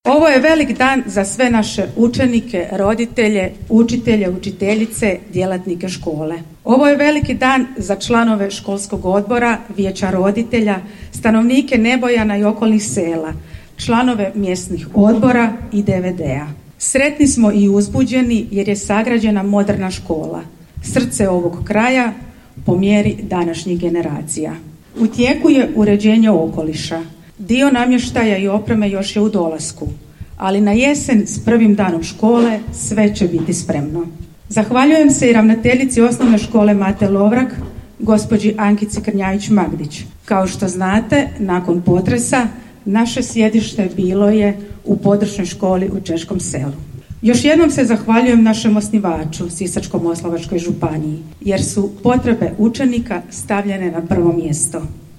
U petak, 28. srpnja 2023. godine, u Nebojanu je svečano otvorena novoizgrađena zgrada Područne škole Osnovne škole Ivan Goran Kovačić Gora.